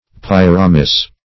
pyramis - definition of pyramis - synonyms, pronunciation, spelling from Free Dictionary Search Result for " pyramis" : The Collaborative International Dictionary of English v.0.48: Pyramis \Pyr"a*mis\, n.; pl.